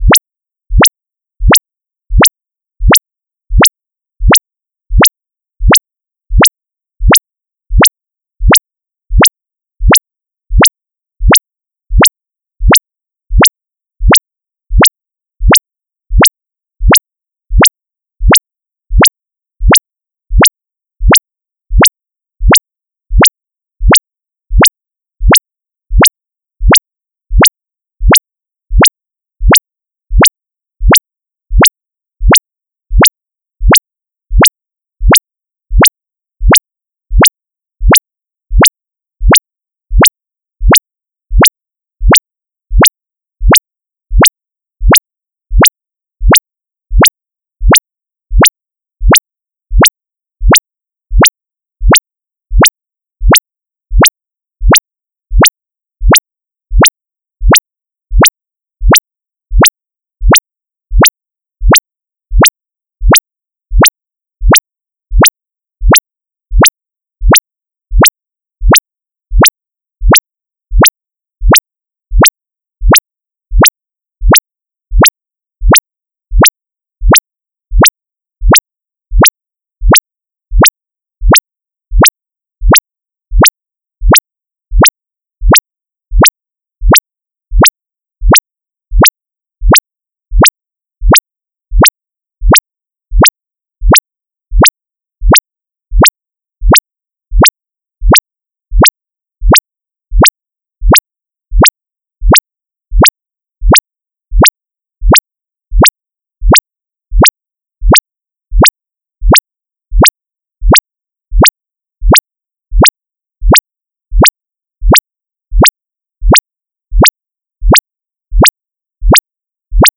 PulsedSineSweep_GAIN.mp3